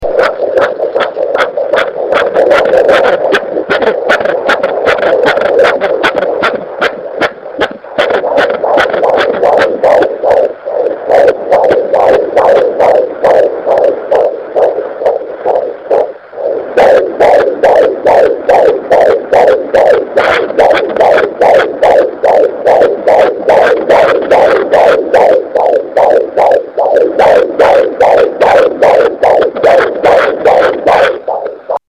The louder sharper thumps (for the first 8 seconds or so and most of the recording) are the baby kicking. The more pounding sound is the heartbeat. These were taken with a digital recorder, then edited with audacity and converted into mp3 using LAME.
heartbeat2.mp3